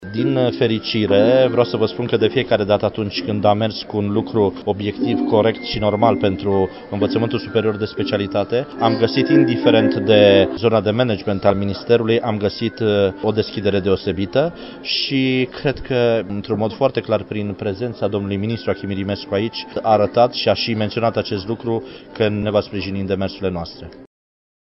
Început de an de studii la Universitatea de Științe Agricole și Medicină Veterinară a Banatului